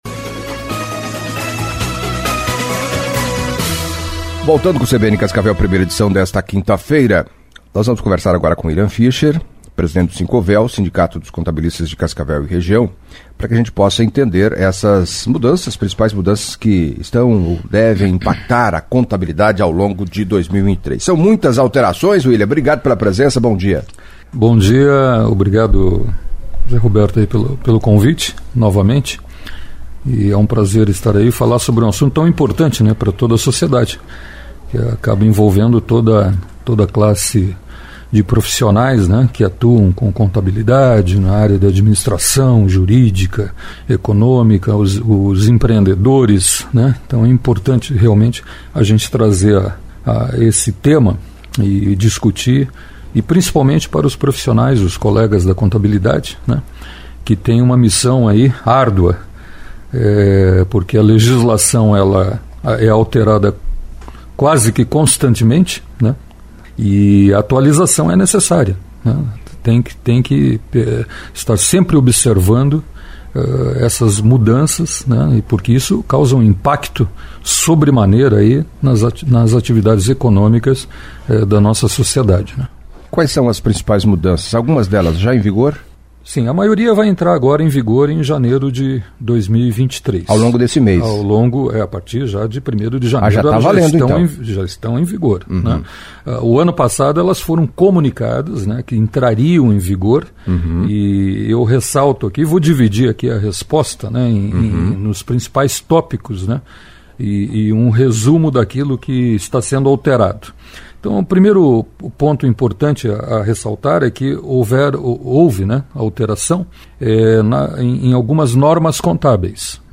Em entrevista à CBN Cascavel nesta quinta-feira